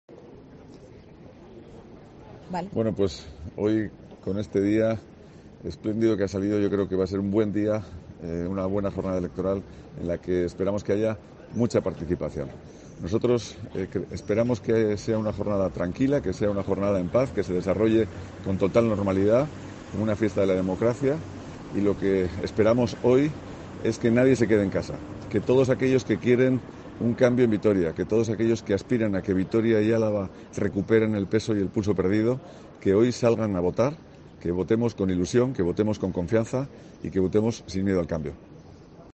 Iñaki Oyarzabal, candidato del PP a diputado general de Álava
Oyarzabal ha votado en el colegio Sagrado Corazón Carmelitas de Vitoria-Gasteiz donde ha asegurado que espera que "con este día espléndido nadie se quede en casa, que sea un día de una gran participación en las urnas, que se desarrolle en paz, en normalidad, que sea una gran fiesta de la democracia".